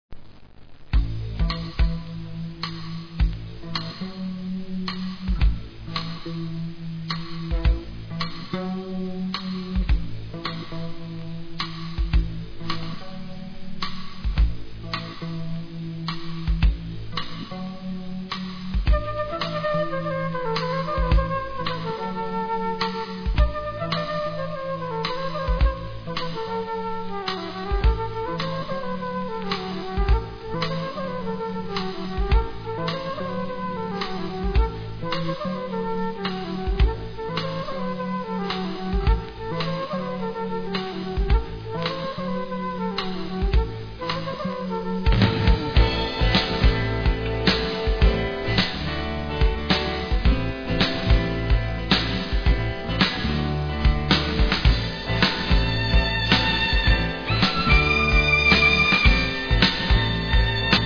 Musica Folk de Cantabria